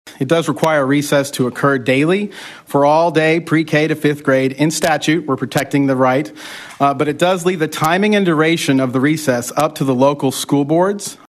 CLICK HERE to listen to commentary from House Member Mickey Dollens.